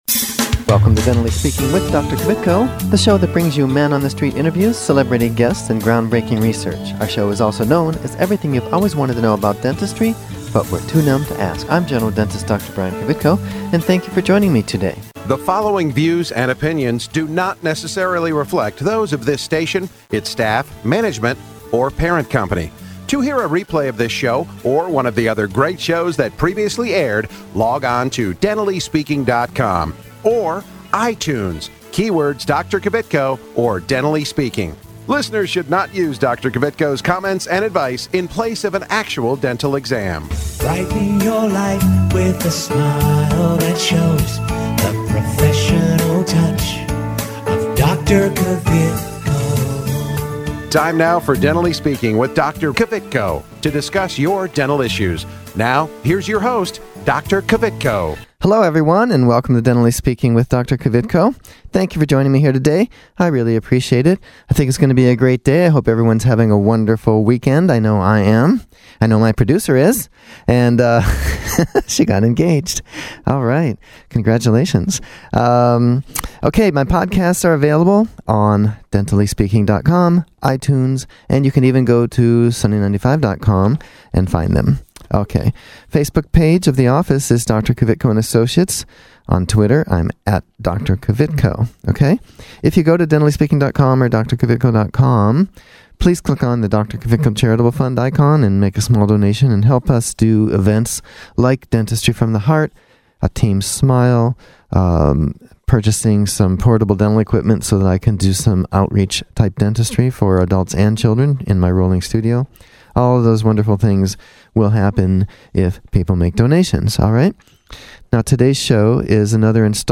Interviews from the Team Smile event with the Cincinnati Bengals